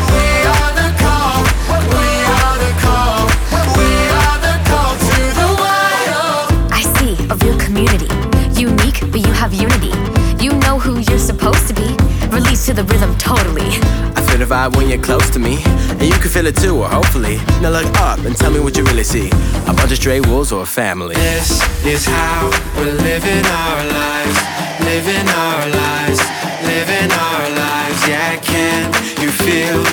• Soundtrack